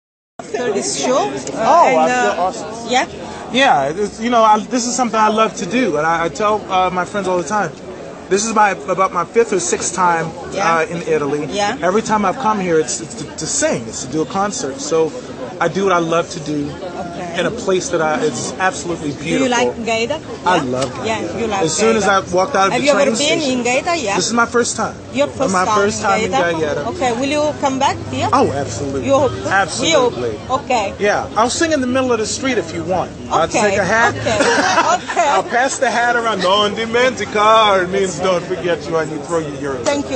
intervista.mp3